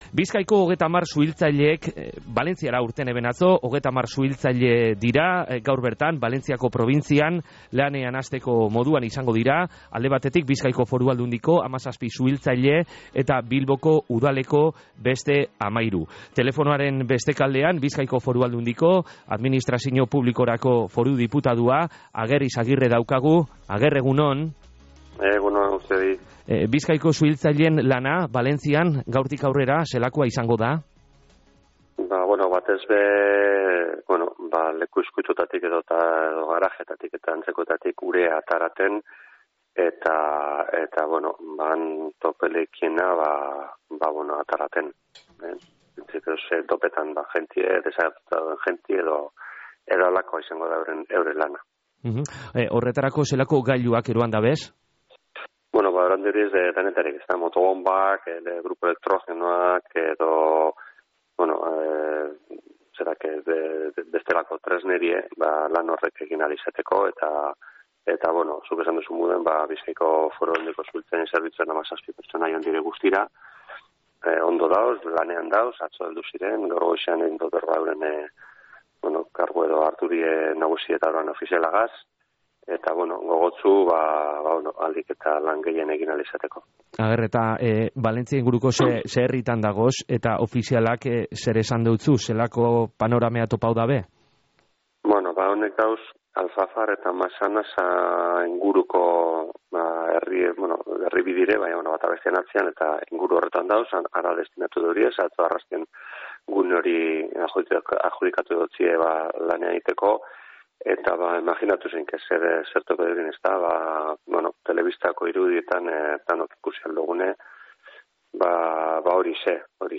Valentziako probintzian biziten dabilzan egoerea guztiz neurriz kanpokoa da” esan deusku Ager Izagirrek, BFAko Administrazino Publikorako foru diputaduak gaur Goizeko Izarretan.